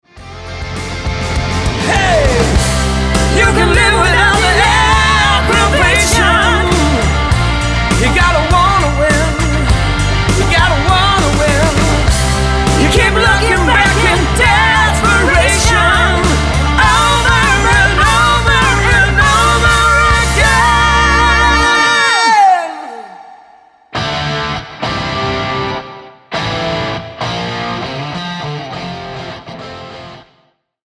Tonart:F Multifile (kein Sofortdownload.
Die besten Playbacks Instrumentals und Karaoke Versionen .